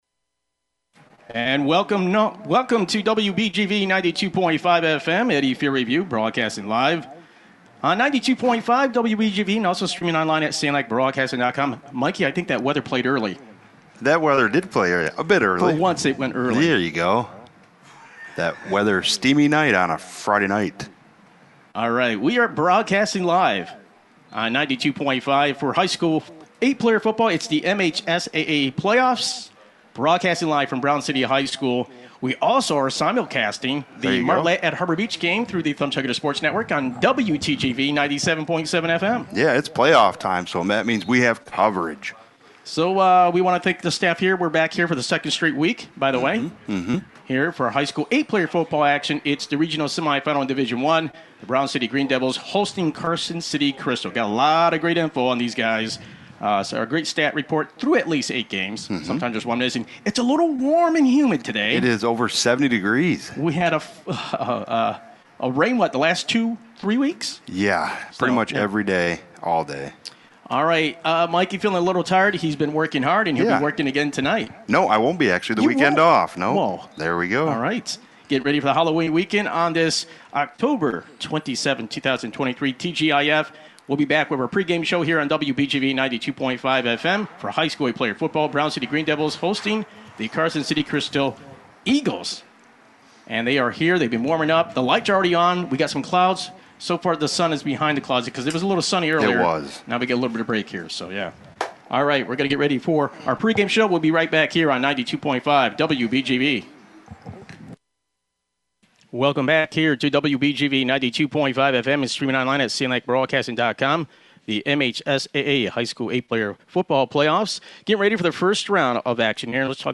8 PLAYER FOOTBALL Division 1 Region 3-1 Semifinal Live Radio Broadcast on WBGV 92.5 FM & online *Brown City 22, Carson City-Crystal 14 Live Broadcast Replay Podcast